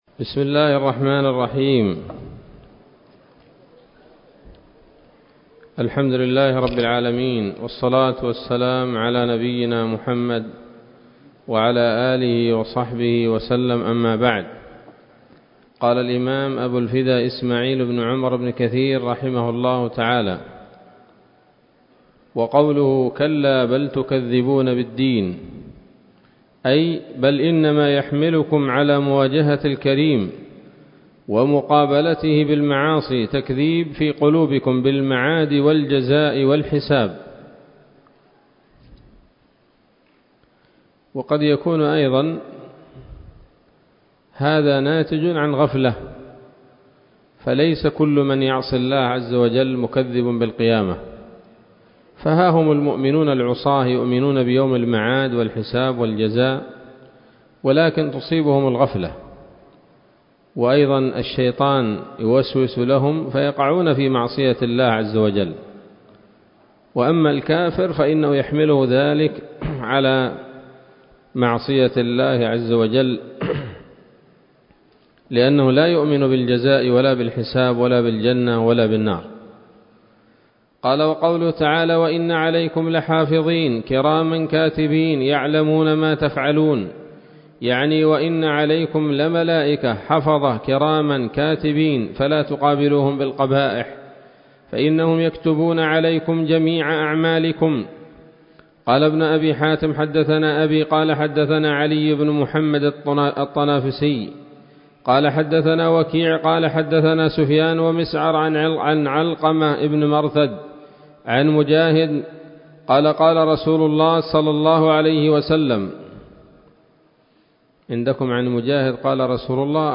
الدرس الثاني وهو الأخير من سورة الانفطار من تفسير ابن كثير رحمه الله تعالى